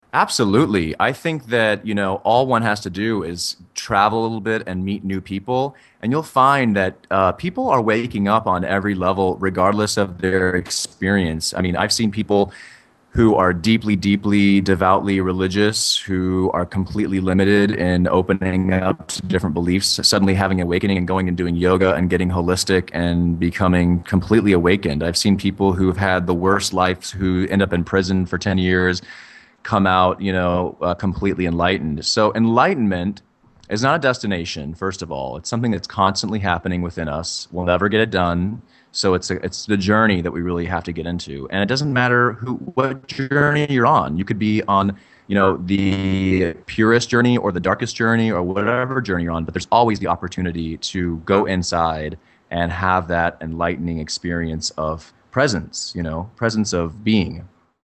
The Interview: